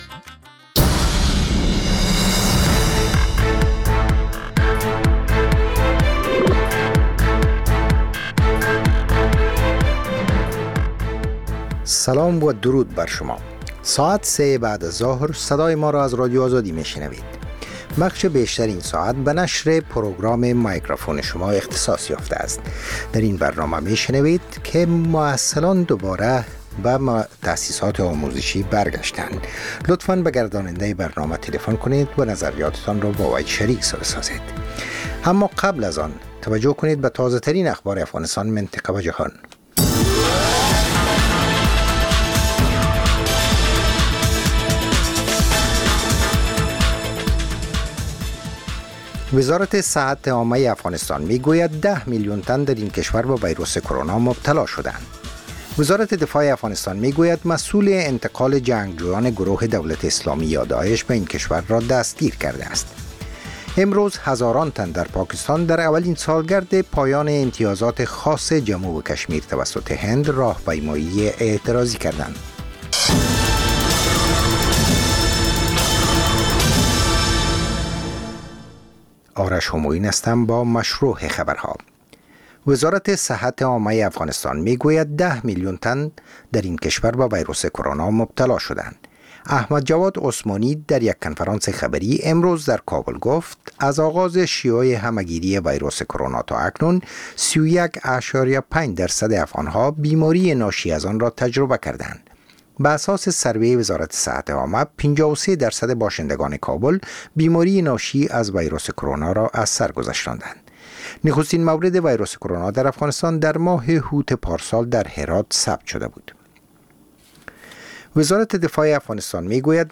خبر ها